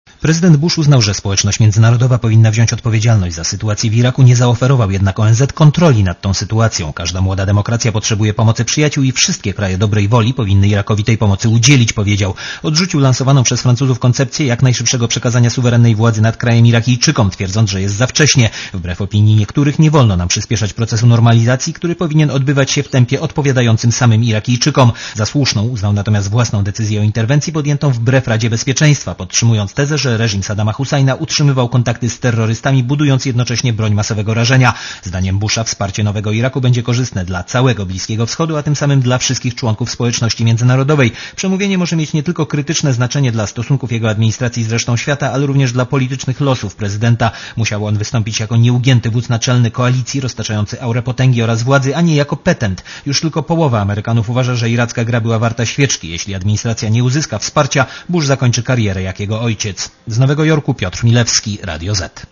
Korespondencja Radia Zet z Nowego Jorku (244Kb)